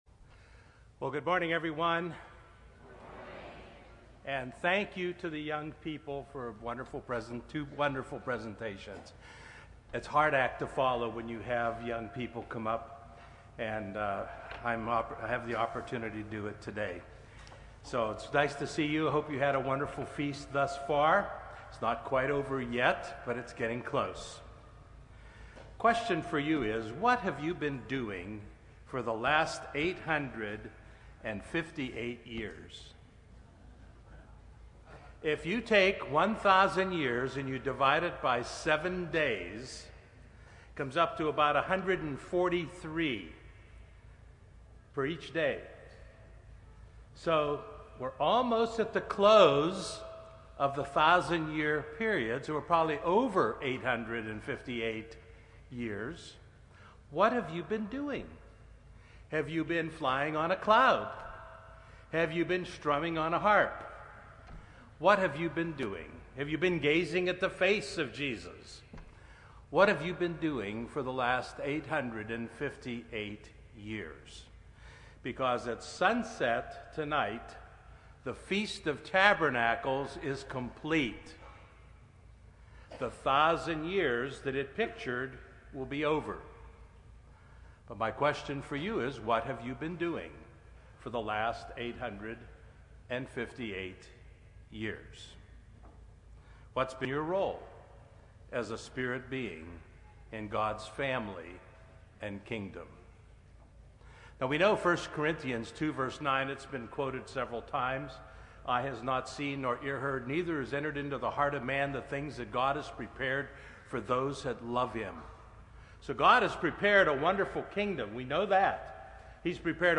This sermon was given at the Oceanside, California 2014 Feast site.